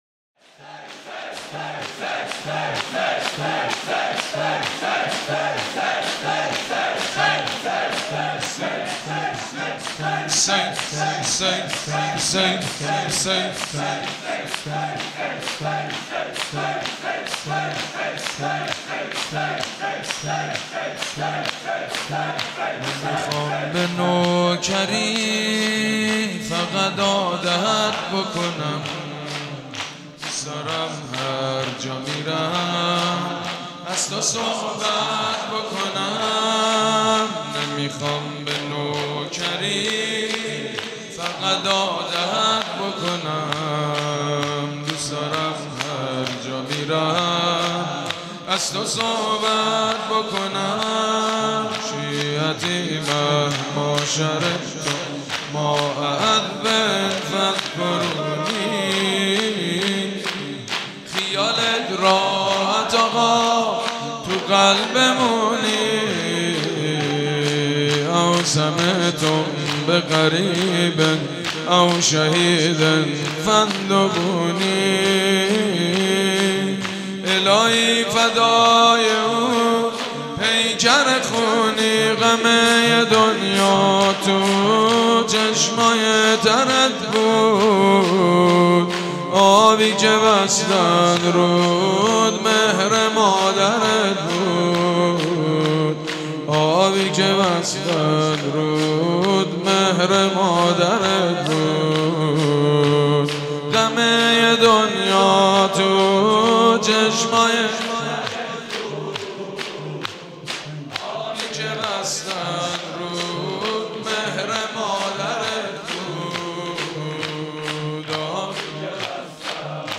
مناسبت : شهادت حضرت فاطمه زهرا سلام‌الله‌علیها1
قالب : شور